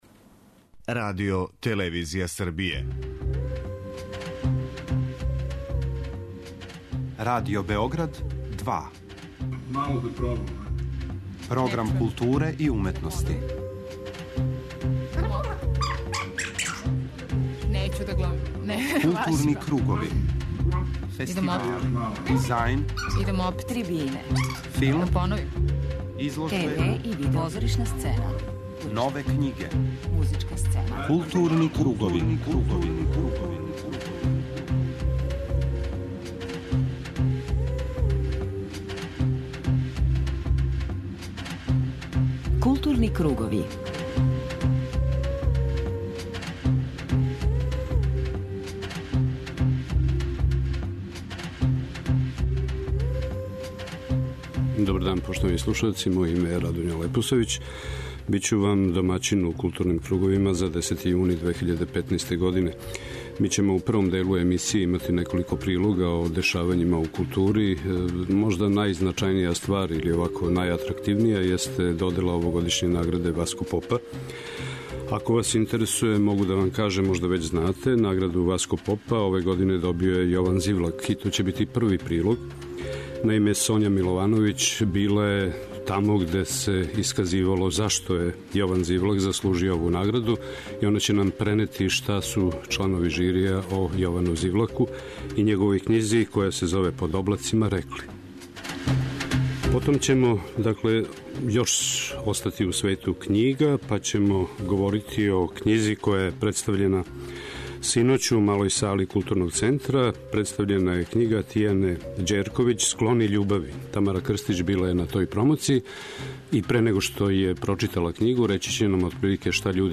преузми : 52.92 MB Културни кругови Autor: Група аутора Централна културно-уметничка емисија Радио Београда 2. Како би што успешније повезивали информативну и аналитичку компоненту говора о култури у јединствену целину и редовно пратили ритам културних збивања, Кругови имају магазински карактер.